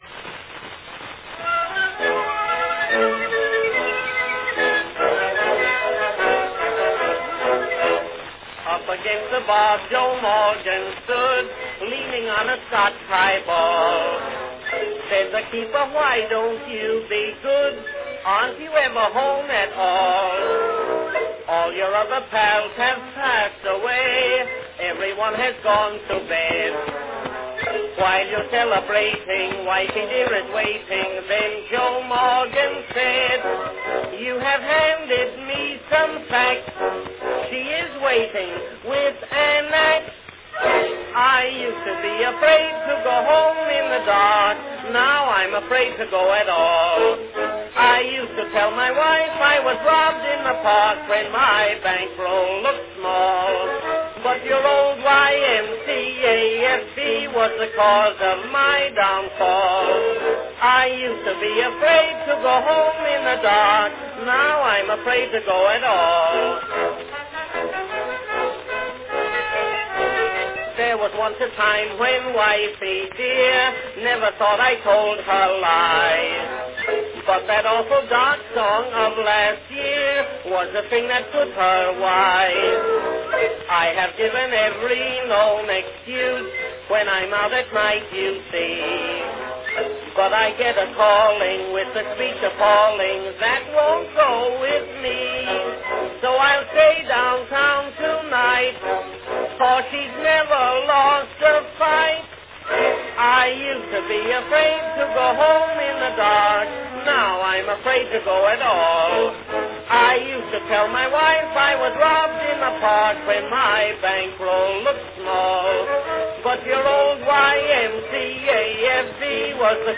its humorous sequel
Category Tenor solo